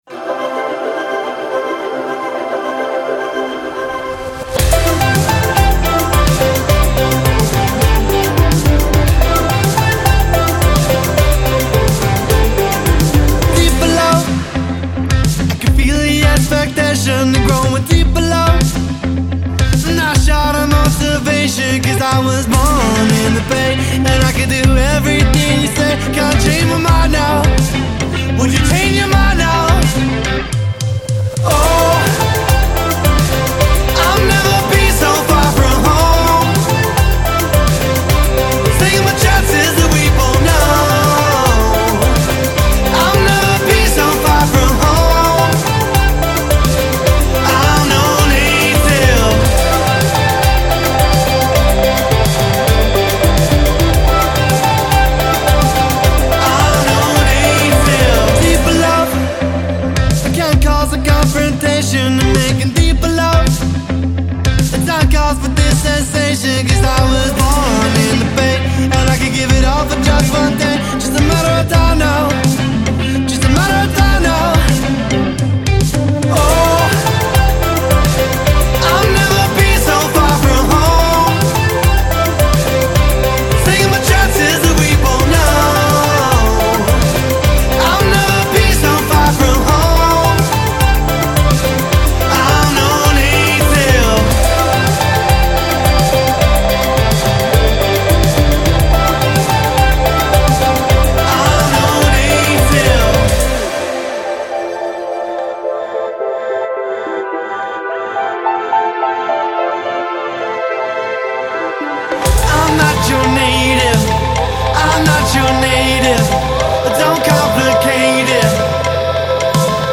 alt-pop four-piece